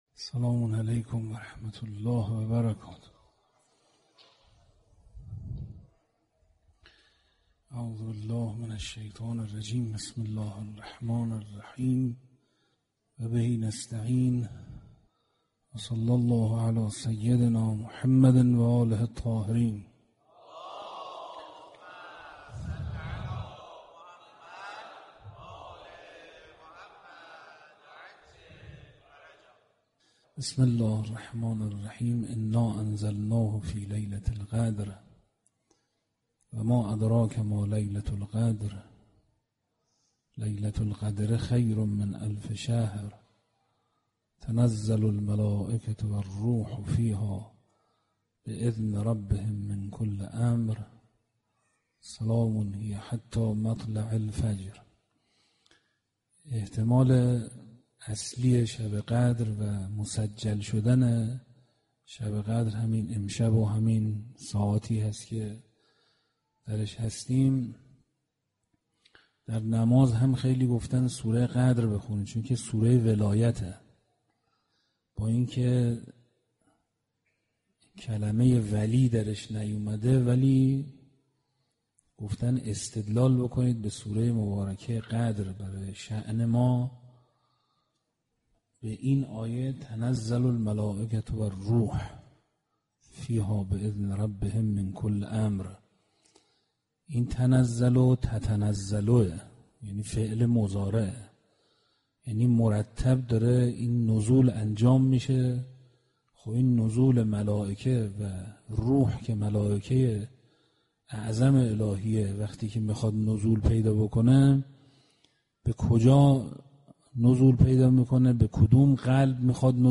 صوت سخنرانی مذهبی و اخلاقی